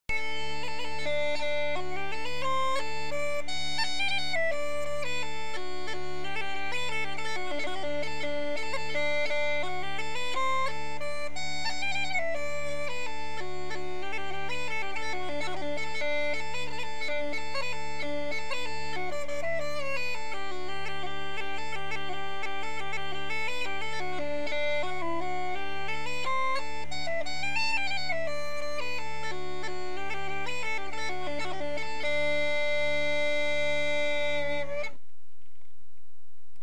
PipingUilleann Pipes
Some sample clips (.wma) of my piping
A Planxty (450k)